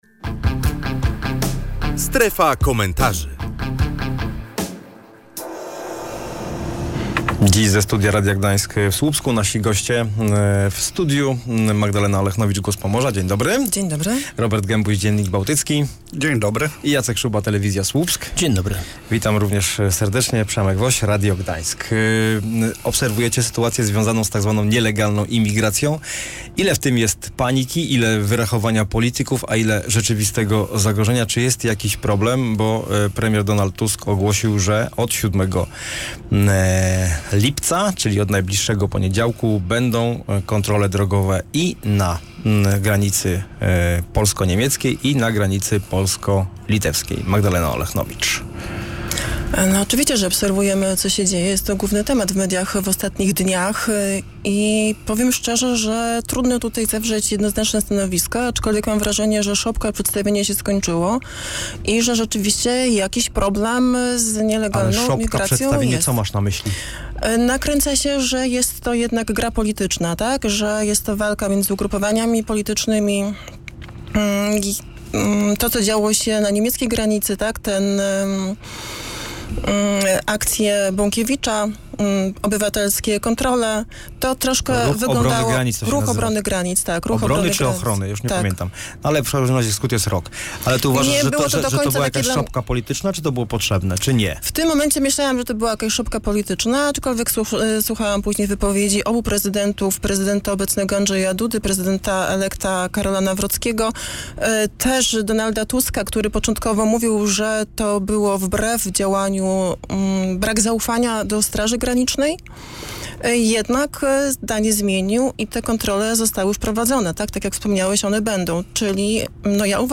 Co z tą nielegalną imigracją? Czy politycy tylko straszą obywateli, czy to tylko polityczna szopka, czy faktycznie mamy poważny problem? Między innymi o tym rozmawialiśmy w „Strefie Komentarzy”.